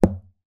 Звуки дротиков
Дартс - Альтернативный вариант